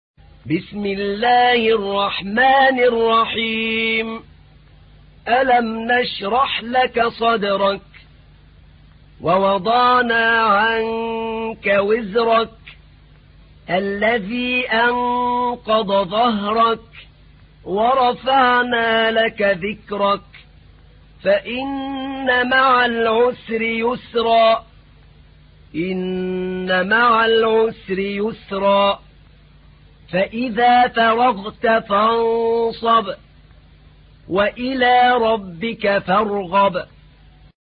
تحميل : 94. سورة الشرح / القارئ أحمد نعينع / القرآن الكريم / موقع يا حسين